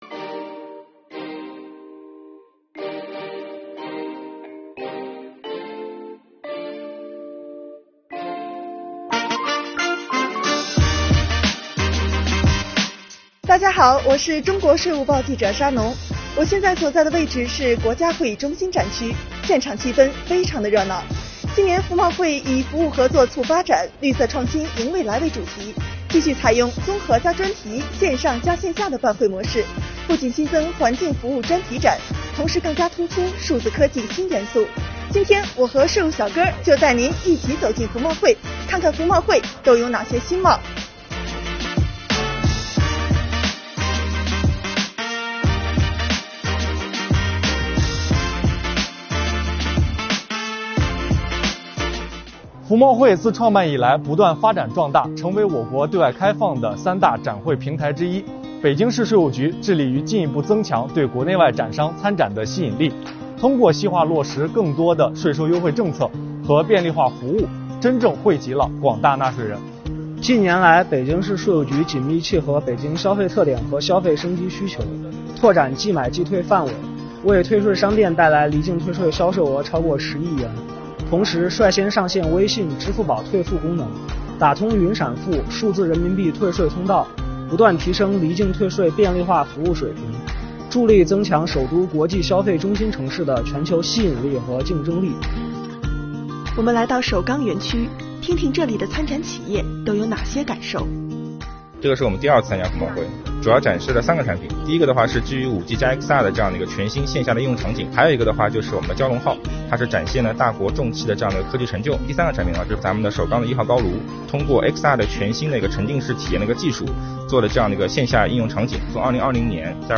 出镜记者